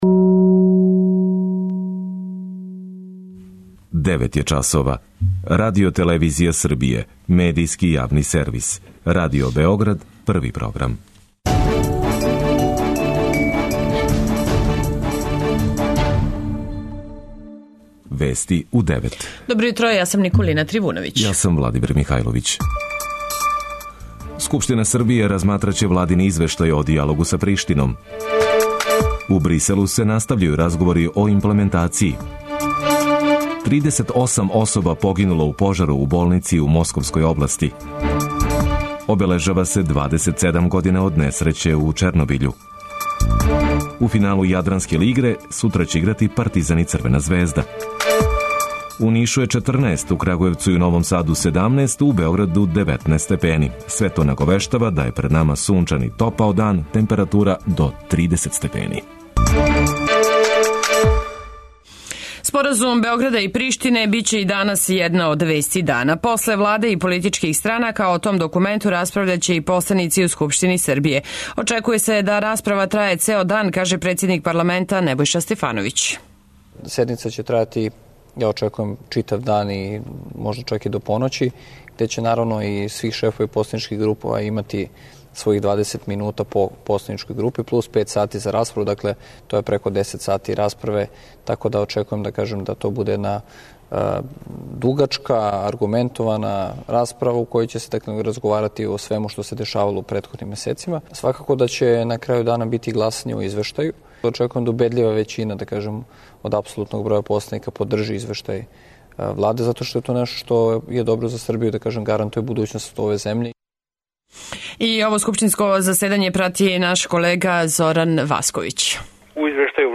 преузми : 9.98 MB Вести у 9 Autor: разни аутори Преглед најважнијиx информација из земље из света.